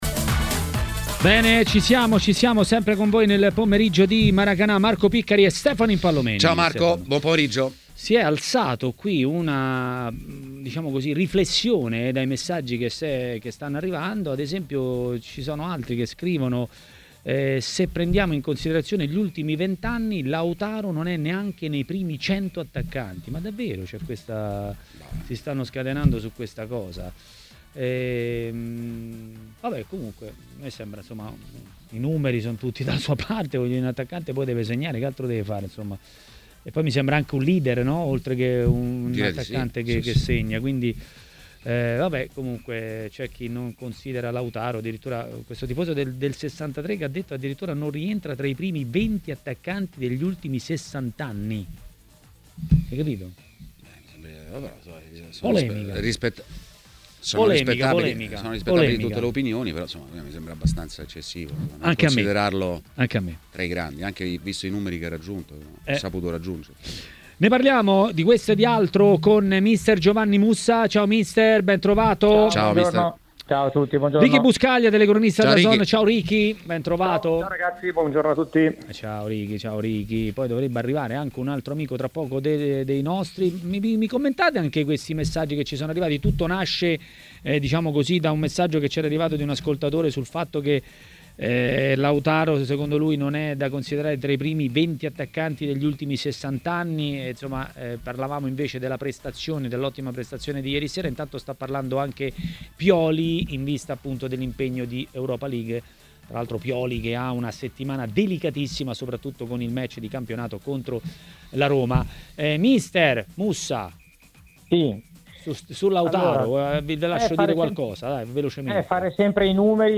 Sezione: Interviste